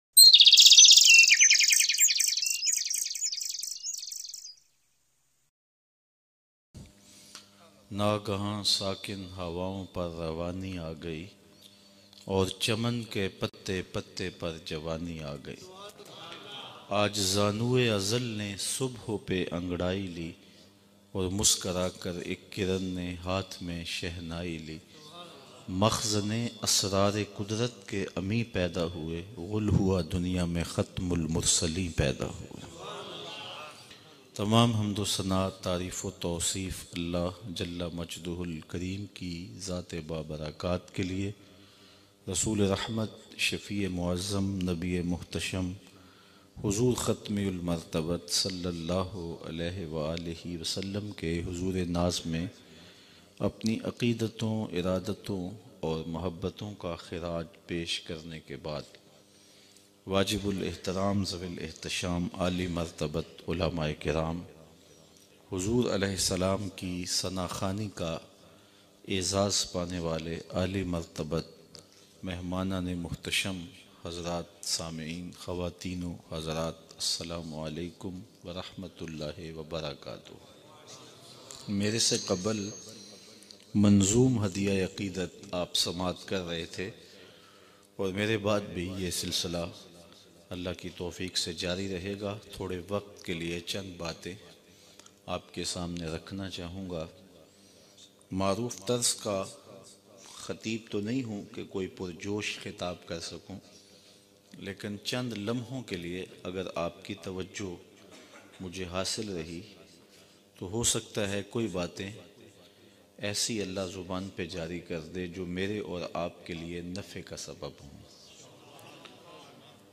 Baara Rabi Ul Awal bayan mp3